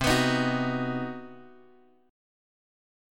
C Minor Major 7th Flat 5th